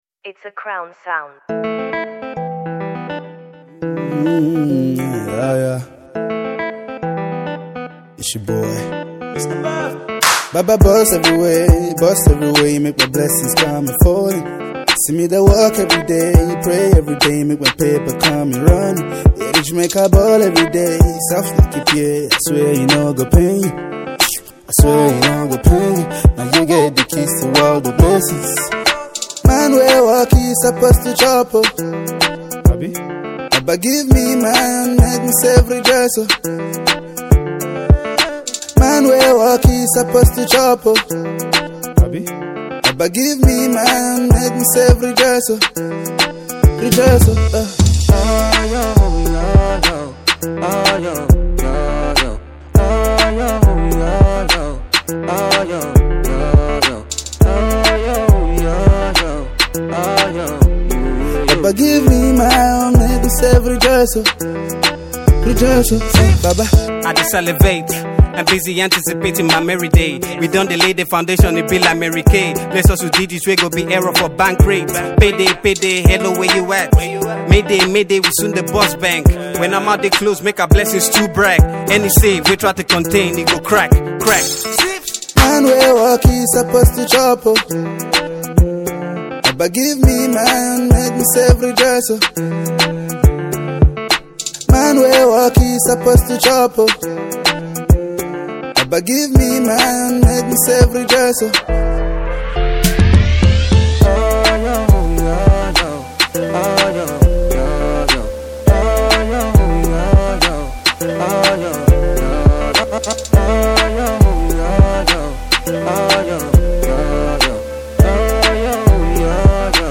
Nigeria’s quintessential rapper
a slow-paced afro-pop tune spiced with fusion